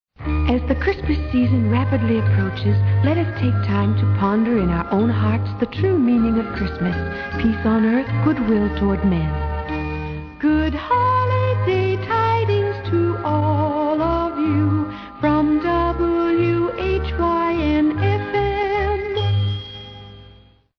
Holiday Jingle